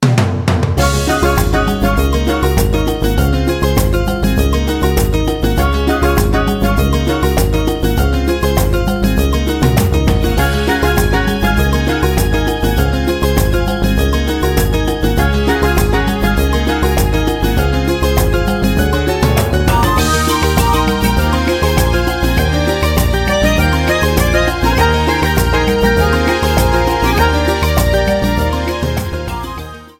Ripped from the ISO
Faded in the end
Fair use music sample